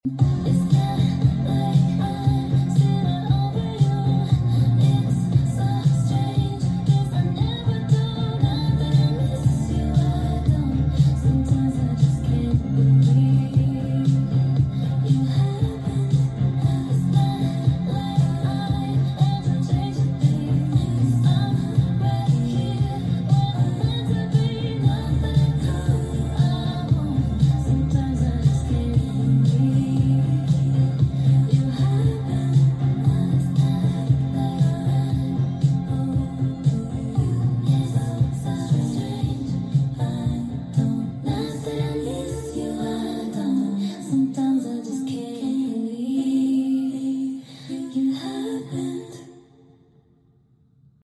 on vinyl